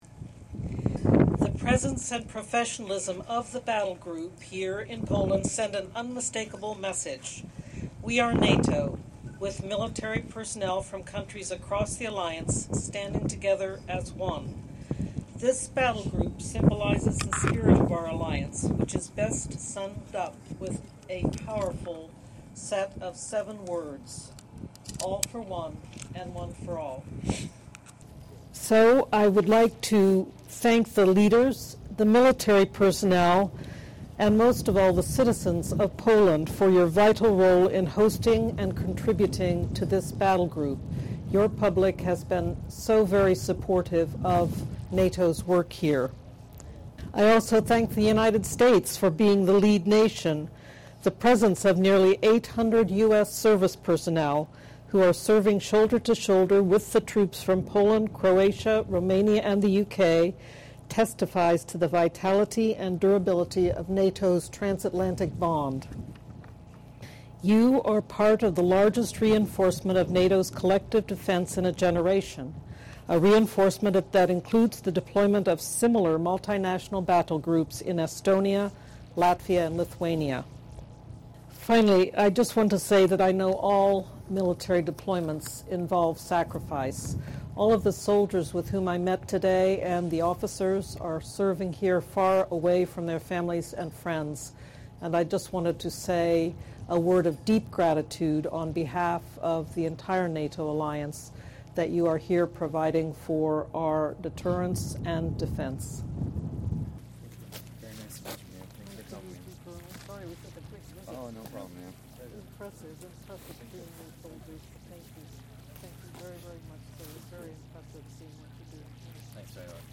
Press conference by NATO Deputy Secretary General Rose Gottemoeller at Warsaw Military Airport 16 Mar. 2018 | download mp3 ENGLISH - Remarks by NATO Deputy Secretary General Rose Gottemoeller at the enhanced Forward Presence facilities in Bemowo Piskie, Poland 16 Mar. 2018 | download mp3